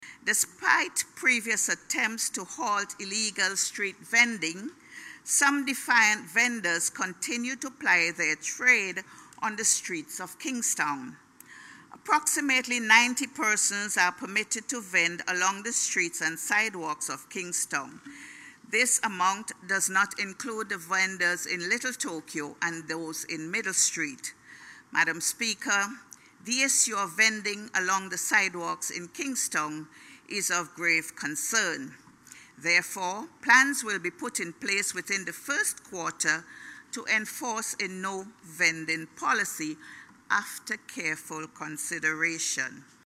Contributing the Budget Debate, Minister of Family and Gender Affairs, Persons with Disability, and Labour, Hon. Laverne Gibson-Velox expressed grave concern about the vending situation.